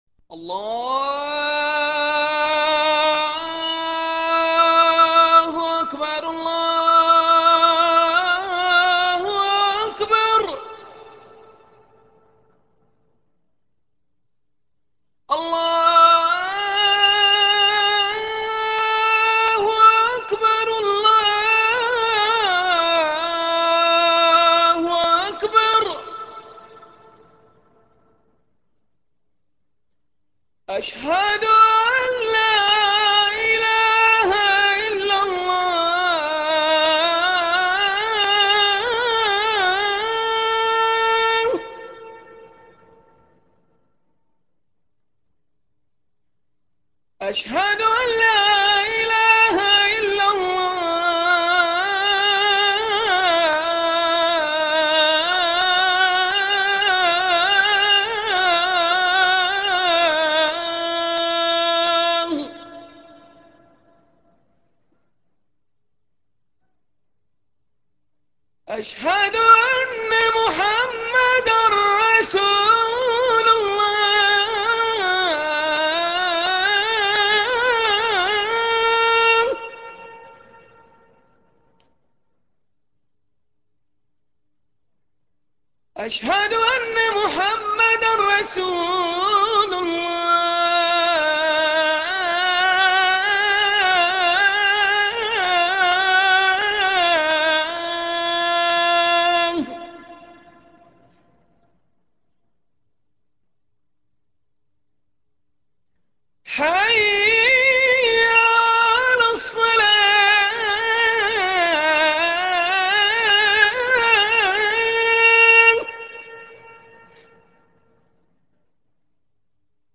adhanmekka.ram